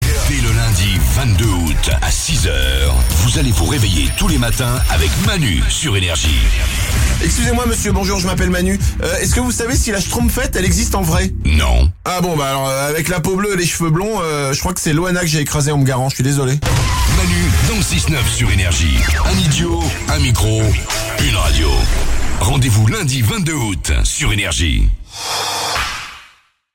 bande annonce n°3 (avec la schtroumpfette)